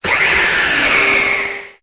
Cri de Lunala dans Pokémon Épée et Bouclier.